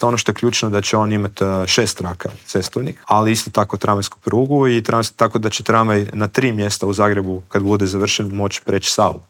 ZAGREB - Premijer, ministri, šefovi oporbenih stranaka, gradonačelnici - svi oni bili su gosti Intervjua tjedna Media servisa.